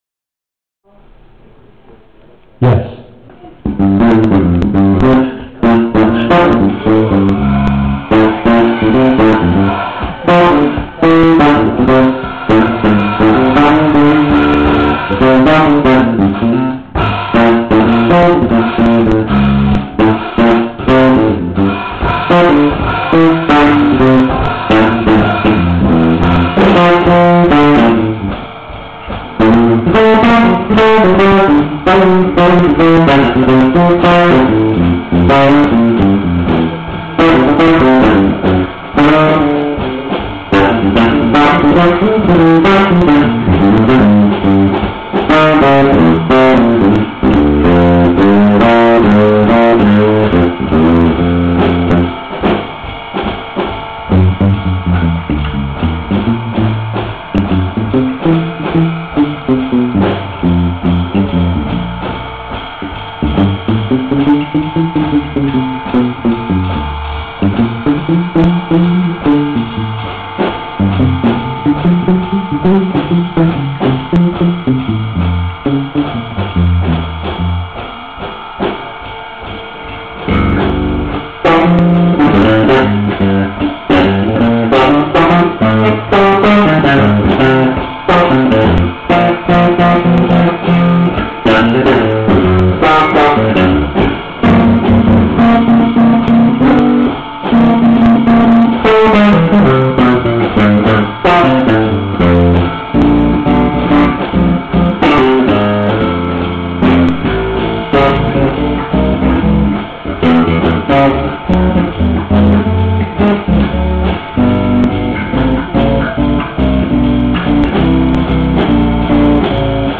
Marcus Miller alla Casa del Jazz a Roma (2006)
Come risposta suona il tema e l’improvvisazione di Scrapple From The Apple di Charlie Parker in stile sassofonistico, contrabbassistico e pianistico]